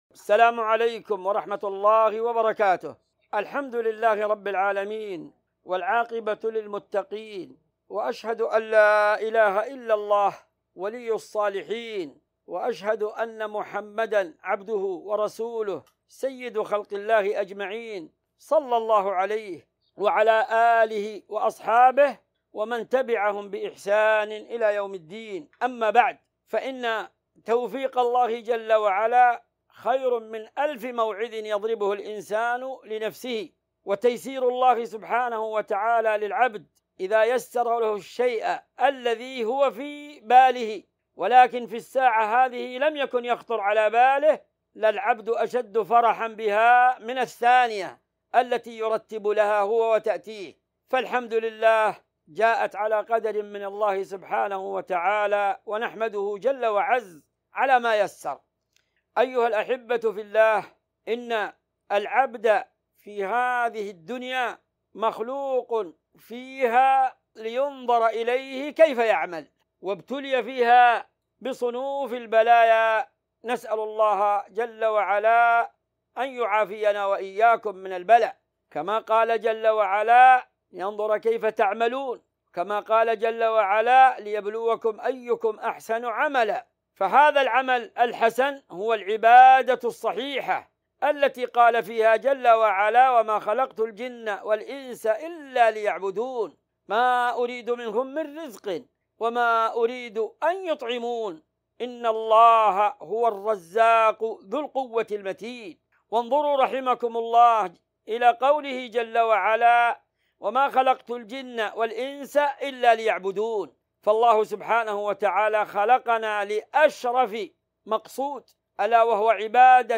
موجهة للإخوة والأبناء بمسجد الإمام الطبري بحي السعادة بانواكشوط في موريتانيا
ألقاها فضيلته بعد عشاء الخميس ٩ ربيع الأول ١٤٤٦ عبر الهاتف من المدينة النبوية.